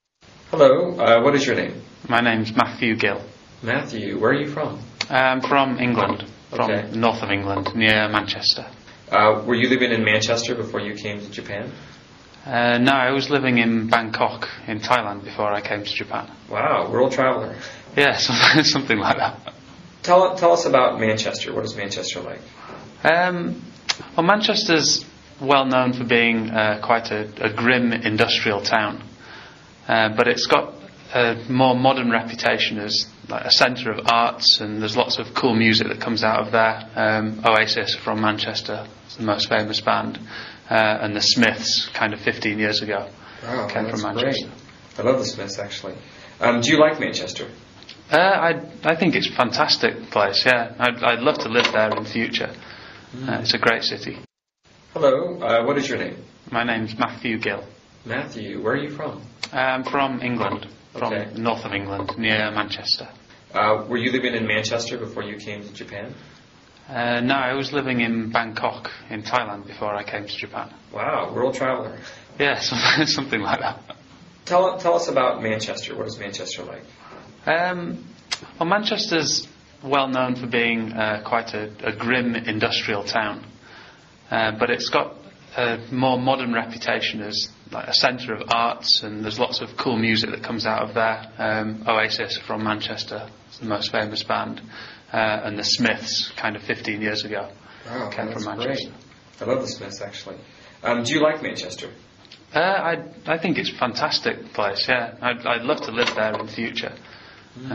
英语高级口语对话正常语速08:曼彻斯特（MP3）